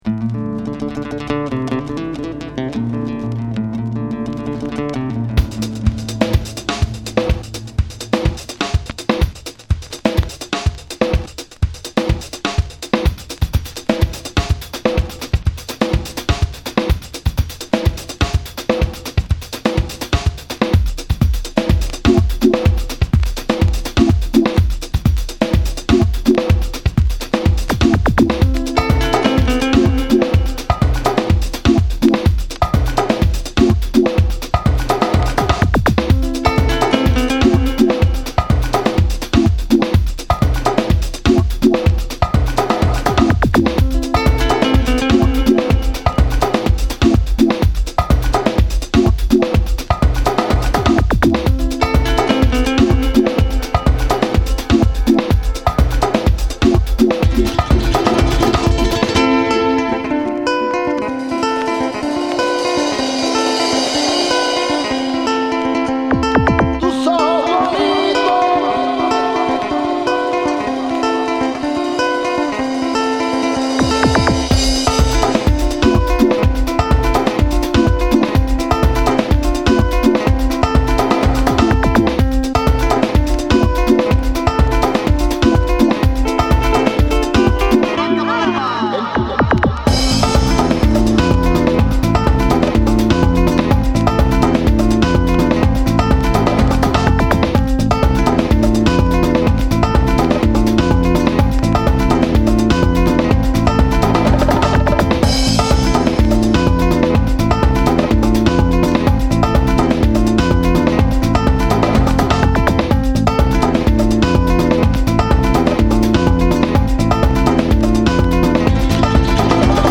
哀愁と空間感覚が心地良いです。